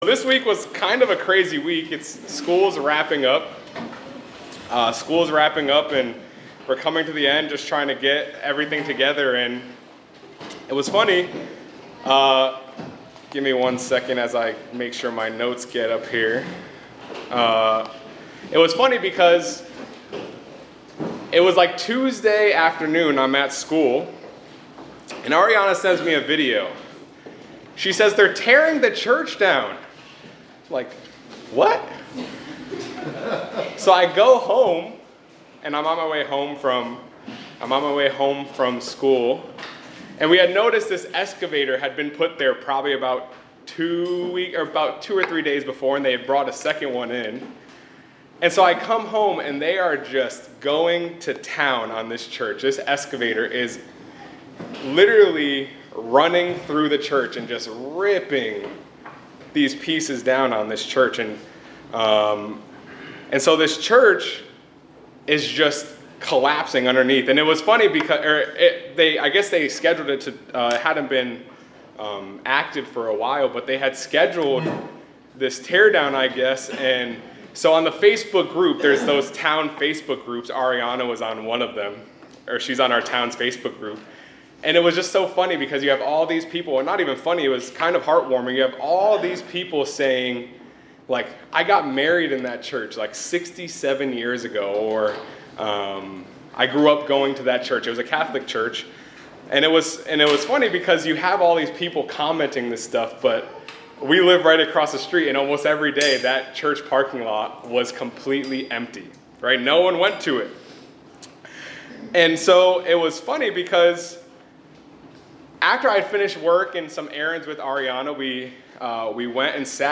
This sermonette reflects on the demolition of a local church and contrasts the common use of "church" as a building, with its biblical meaning as a community of believers. It discusses the historical context of worship in temples and how God's presence was associated with specific locations, referencing biblical texts, and discussing where the Glory of God resides today.
Given in Hartford, CT